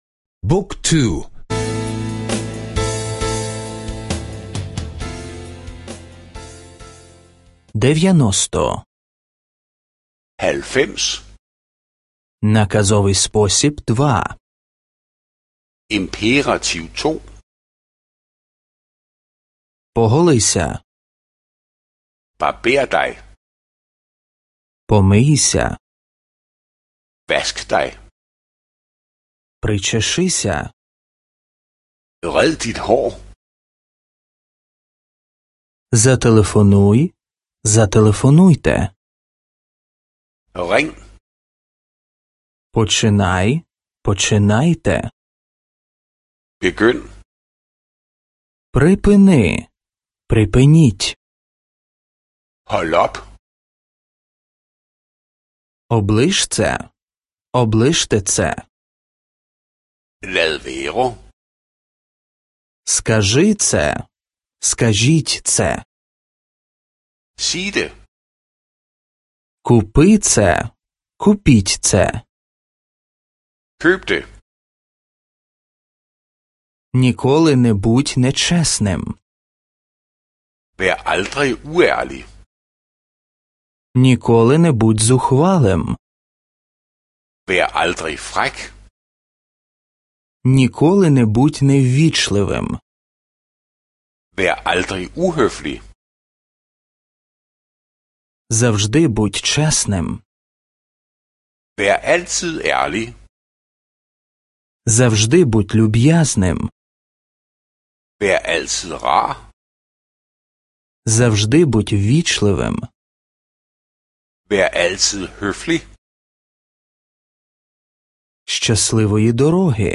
Аудіо уроки данської мови — слухати онлайн